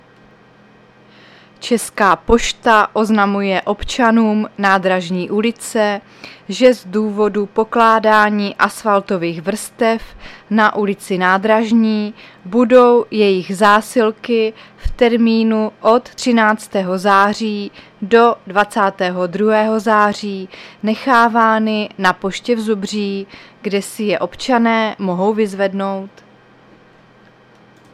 Záznam hlášení místního rozhlasu 14.9.2023